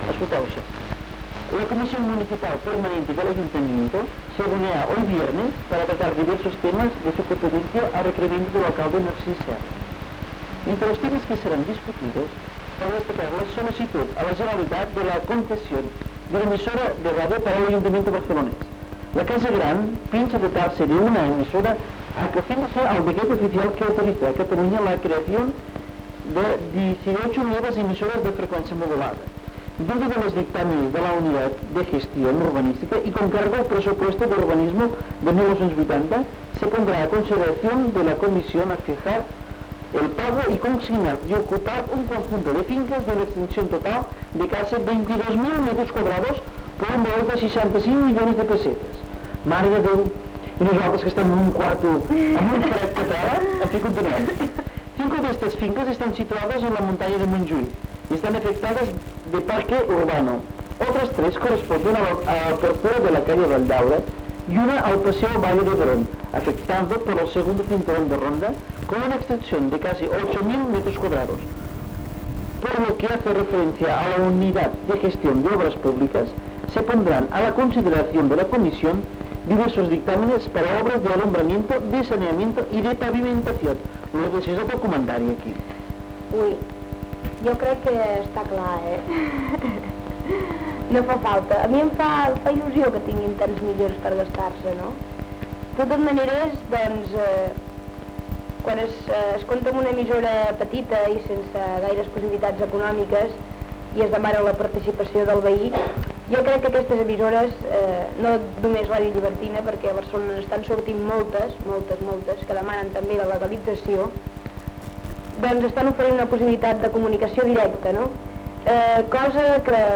94a9461d87579c48c2027e950f6b120e8d77aedc.mp3 Títol Ràdio Llibertina Emissora Ràdio Llibertina Titularitat Tercer sector Tercer sector Lliure Descripció Valoració de la reunió de la comissió municipal de l'Ajuntament de Barcelona per demanar una emissora municipal i funció de Ràdio Llibertina i futura campanya d'ajuda a l'emissora. Gènere radiofònic Informatiu